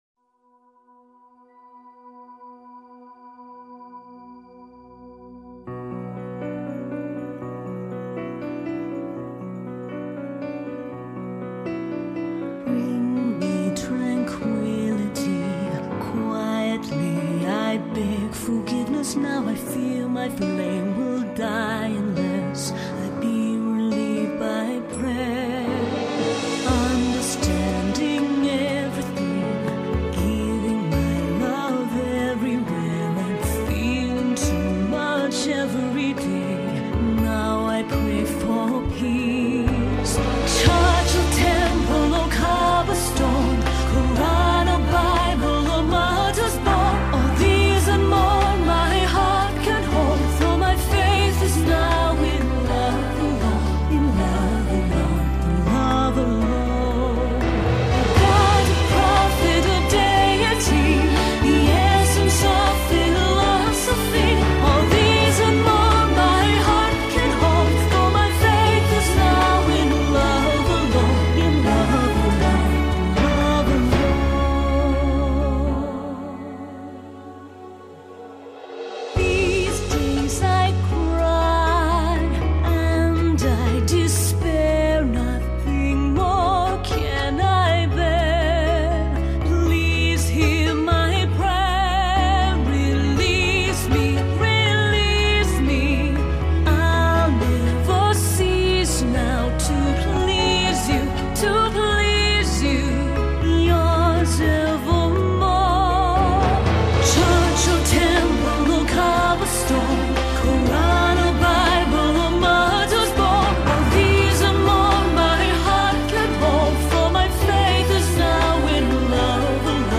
西洋歌曲
擁有乾淨而清亮的嗓音
清新甜美的歌聲在起承轉合之中流露出豐富的情感